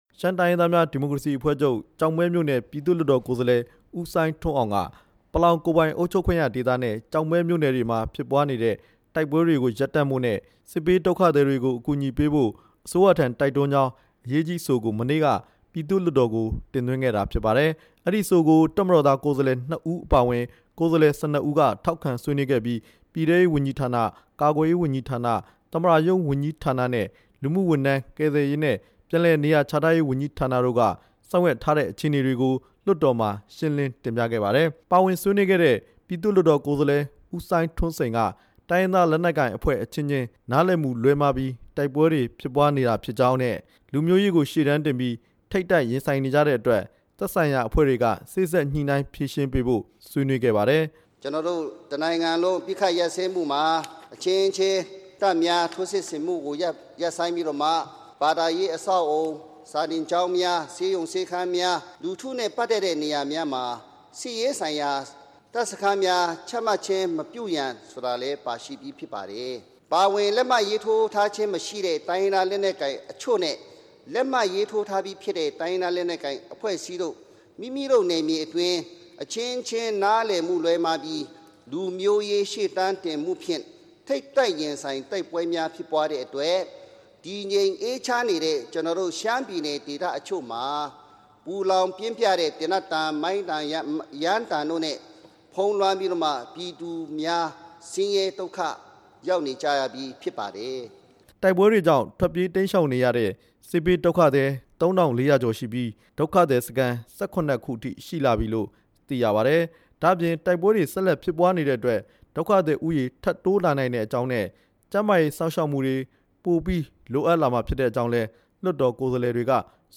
လွှတ်တော်ကိုယ်စားလှယ် ၁၂ ဦးနဲ့ သက်ဆိုင်ရာဝန်ကြီးဌာန တာဝန်ရှိသူတွေရဲ့ ပြောကြားချက်တချို့ကို